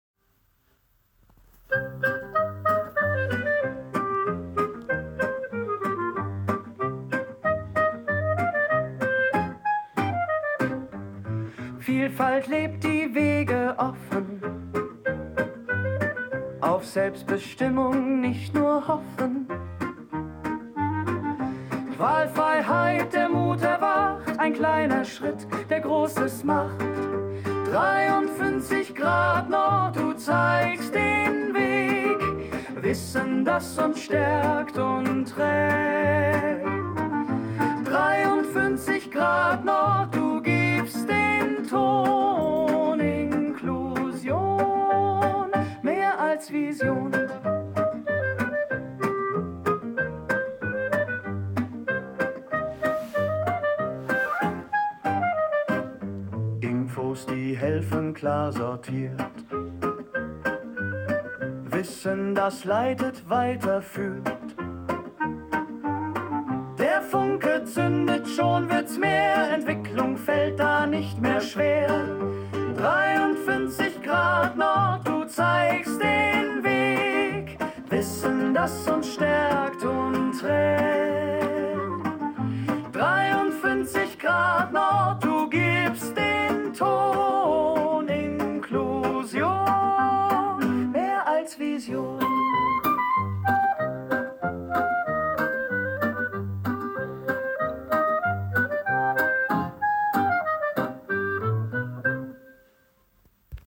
KI komponiert Song für 53° NORD